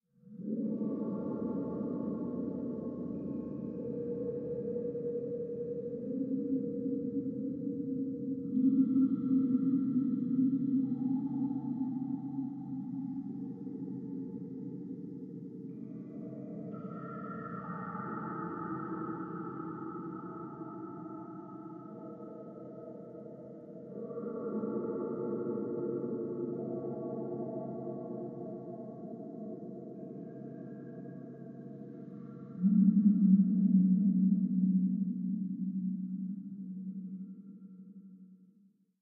Cave Moaning Deep Ambience - Light Distant Drops And Babbles, Cave Tones Air Howls